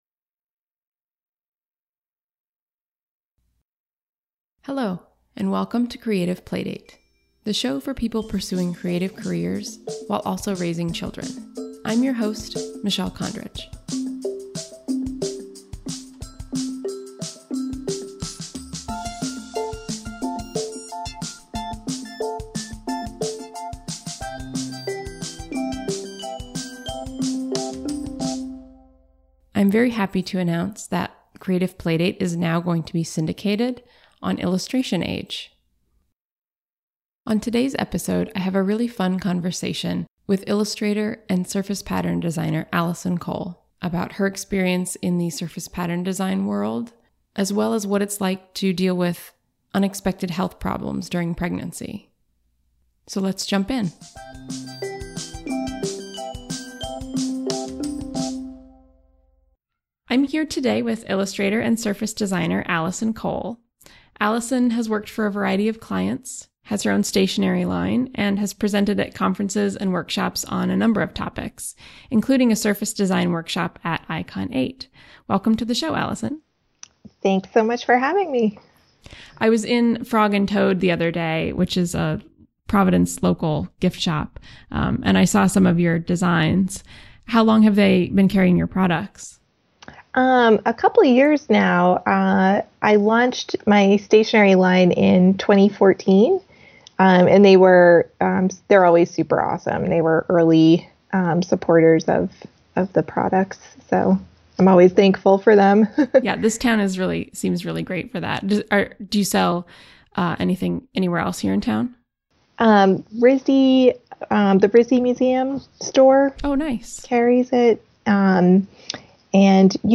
We had a fun conversation about what it’s like to work in surface pattern design and licensing and we discovered that our lives are shockingly similar – our husbands even work at the same school. She talks about her crazy-long commute while teaching out of state and about dealing with a serious health problem during pregnancy.